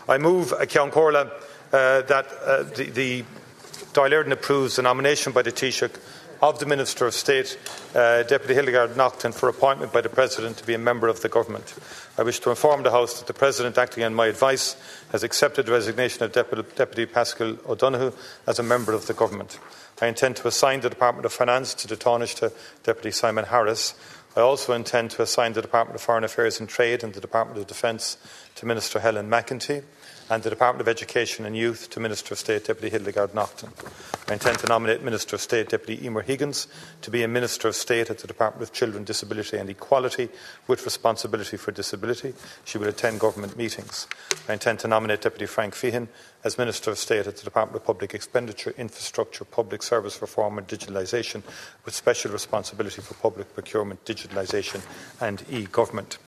Making the announcements in the Dail, Taoiseach Michael Martin also confirmed that Sligo, Leitrim and South Donegal TD Frank Feighan is returning to the junior ministerial ranks……………
taoiseach5pm.mp3